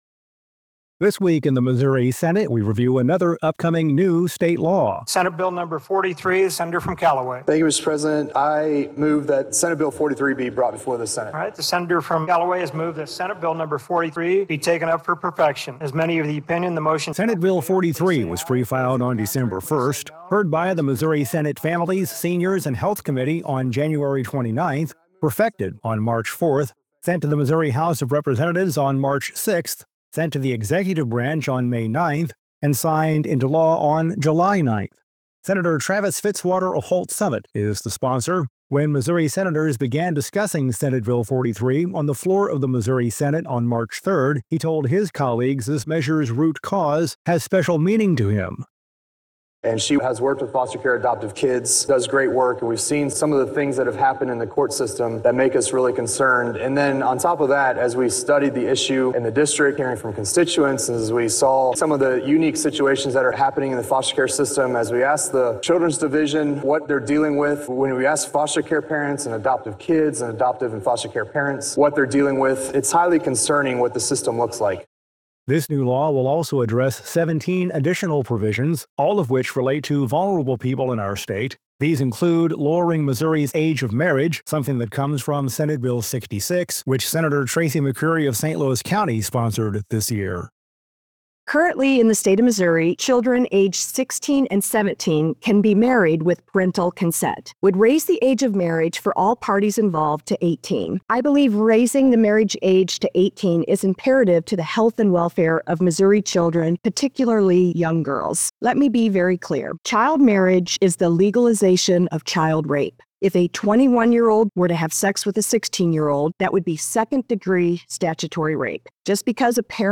Every Friday, Senate Communications offers This Week in the Missouri Senate, a wrap up of the week’s actions that includes audio from floor debate, committee hearings and one-on-one interviews with the senators themselves.